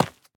latest / assets / minecraft / sounds / block / tuff / break5.ogg
break5.ogg